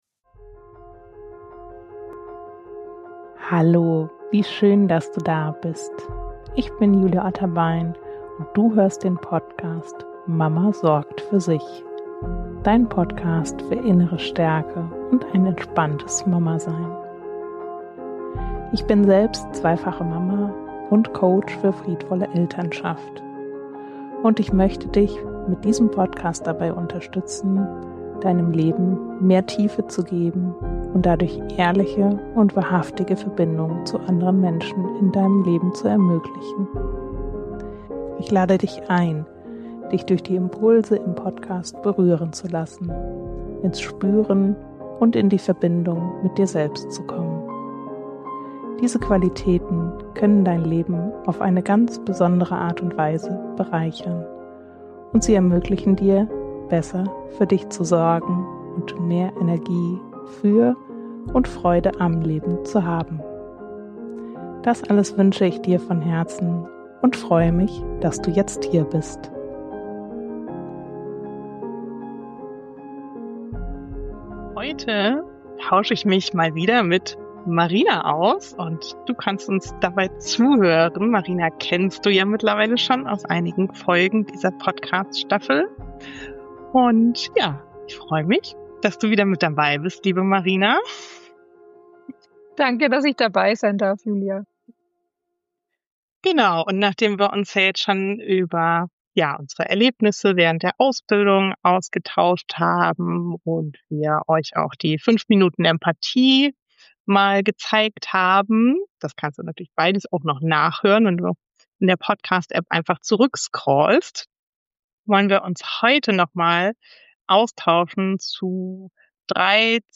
Du erfährst, warum dein innerer „Bioladen“ vielleicht schon viel mehr zu bieten hat, als du denkst, und weshalb Absichtslosigkeit eine der sanftesten und zugleich schwierigsten Qualitäten im Coaching sein kann. Ein Gespräch voller Wärme, Lachen, Erkenntnisse & Nähe.